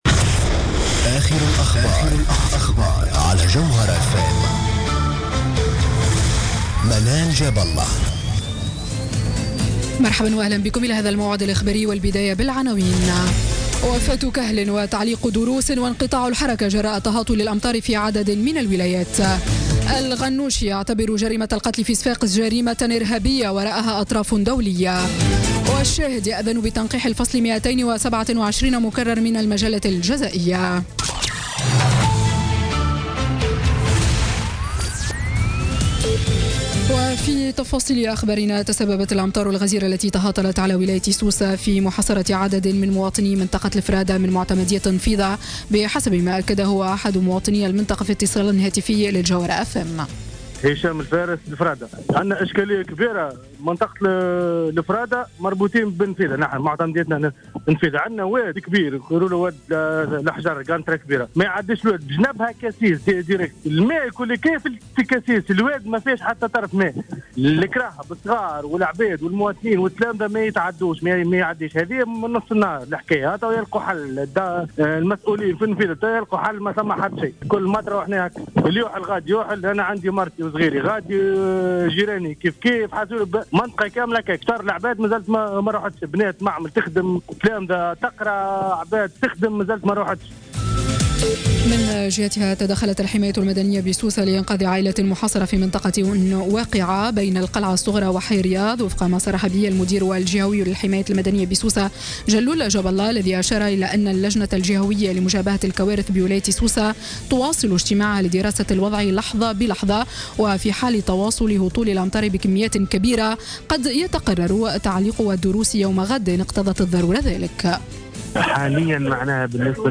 نشرة أخبار السابعة مساء ليوم الجمعة 16 ديسمبر 2016